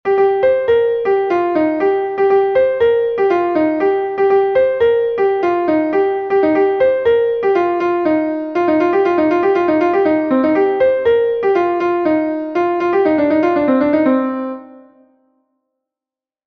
An dro Arzh is a An dro from Brittany